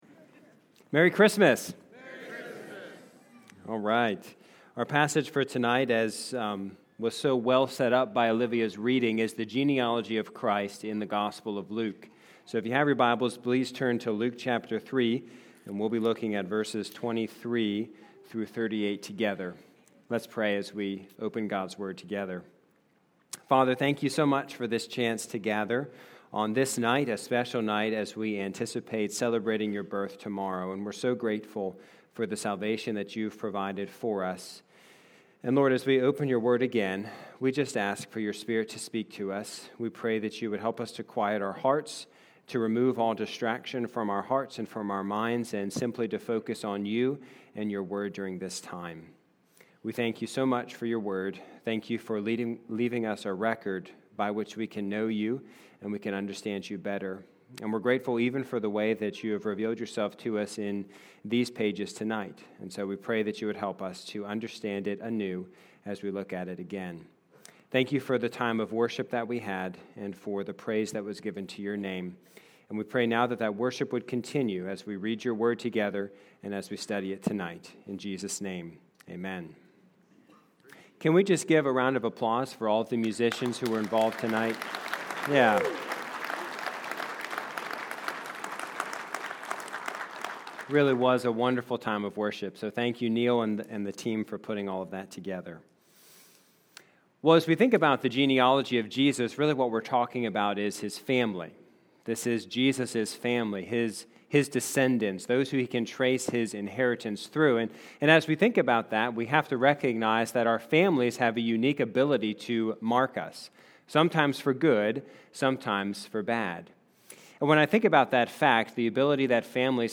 Christmas Eve Service | The Geneology of Christ